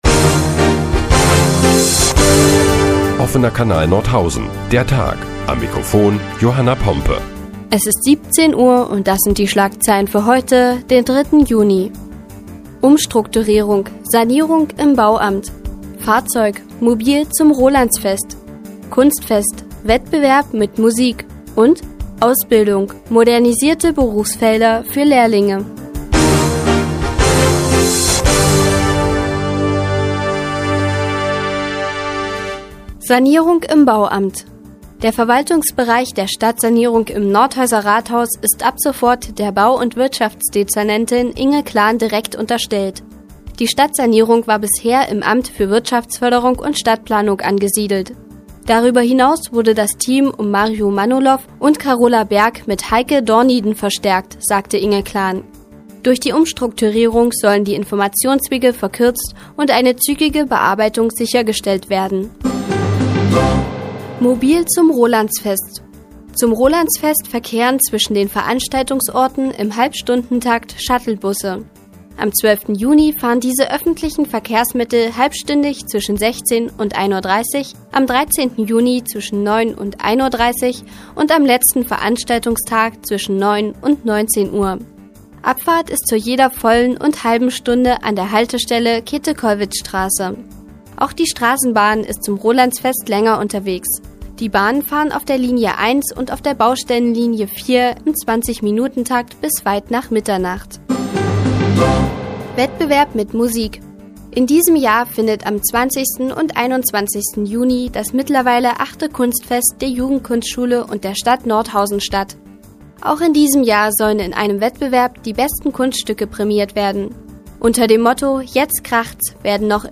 Die tägliche Nachrichtensendung des OKN ist nun auch in der nnz zu hören. Heute geht es unter anderem um eine Umstrukturierung im Bauamt und neue Ausbildungsmöglichkeiten für Lehrlinge.